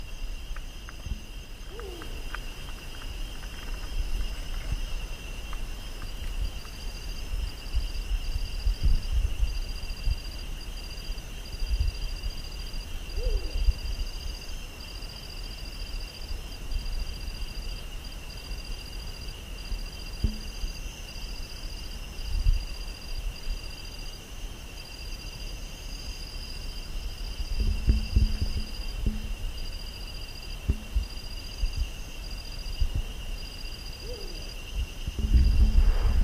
Lechuza Negra (Strix huhula)
Nombre en inglés: Black-banded Owl
Localidad o área protegida: Parque Provincial Caá Yarí
Condición: Silvestre
Certeza: Vocalización Grabada